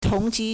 同期 tóngqī
tong2qi1.mp3